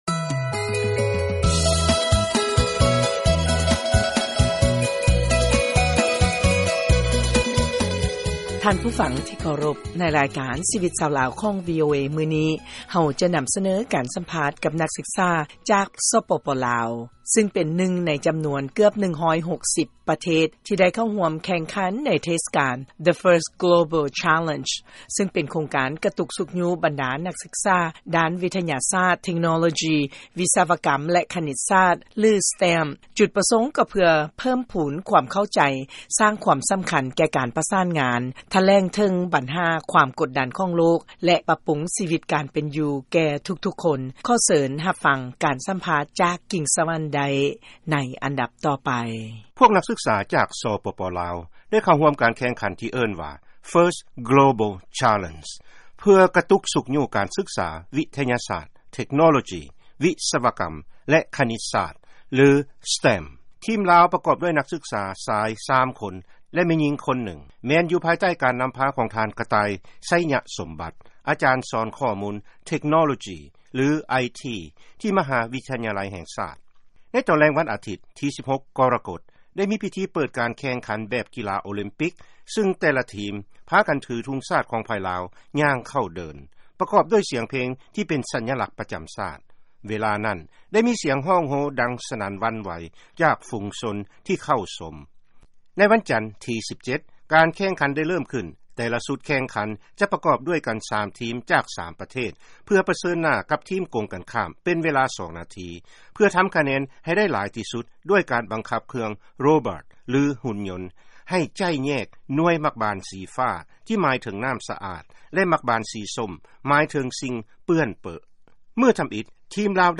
ເຊີນຟັງລາຍງານ ແລະການສຳພາດ ນັກສຶກສາ ສປປ ລາວ ເຂົ້າຮ່ວມ First Global Challenge.